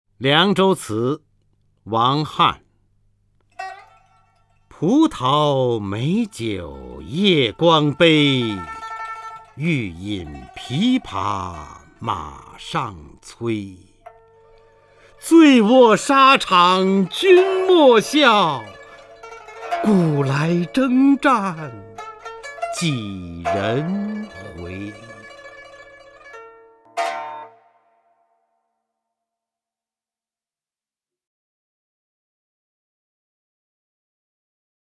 首页 视听 名家朗诵欣赏 方明
方明朗诵：《凉州词·葡萄美酒夜光杯》(（唐）王翰)